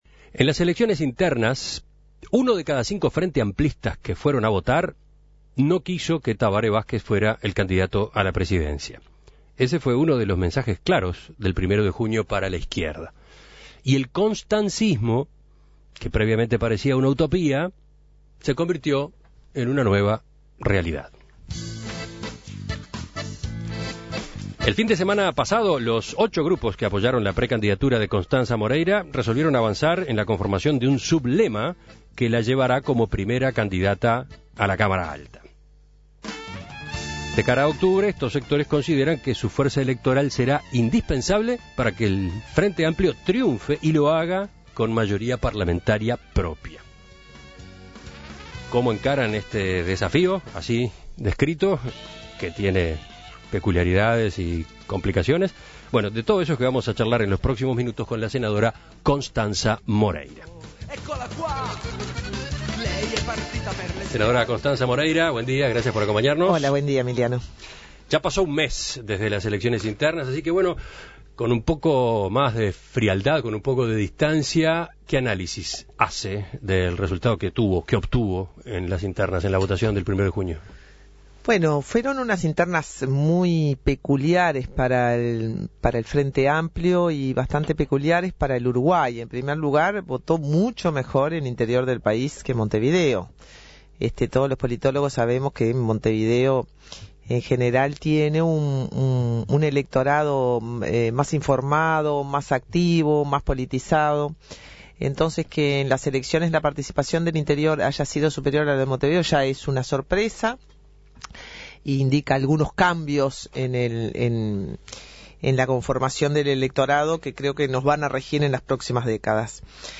En Perspectiva habló con la senadora para conocer el balance que hace sobre los resultados de las elecciones del mes pasado y el camino hacia las nacionales.